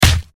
Punch8.wav